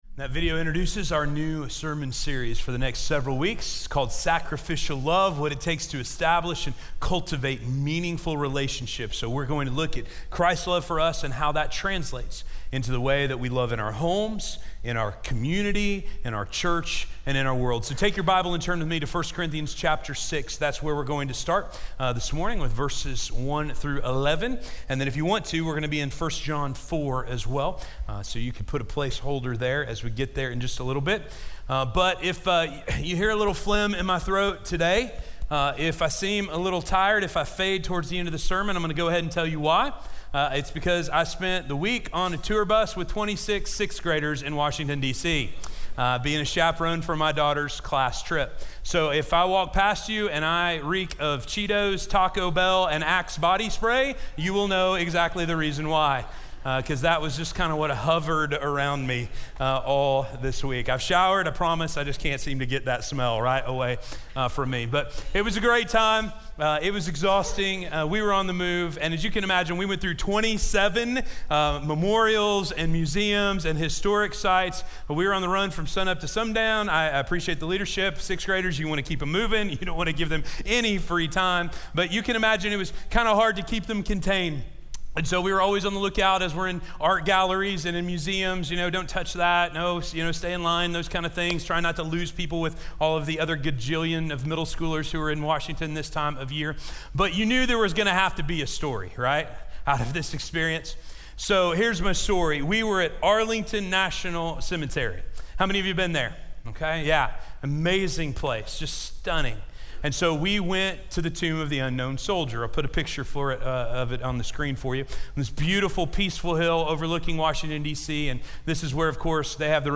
Not Who We Used to Be - Sermon - Station Hill